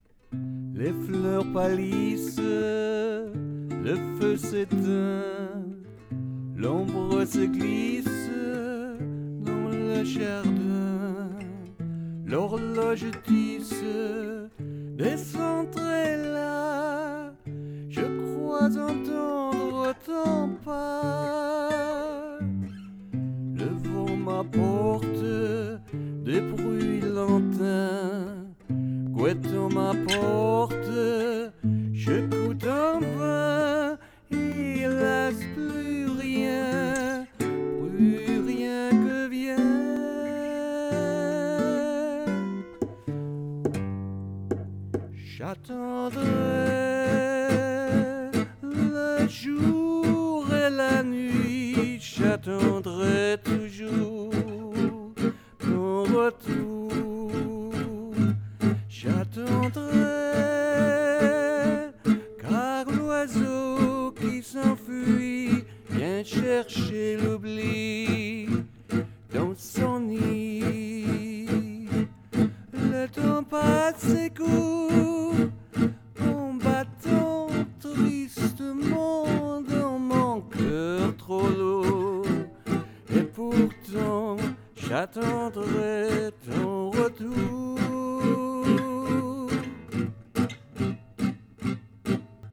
Here is a vocal version, an octave higher than the old one.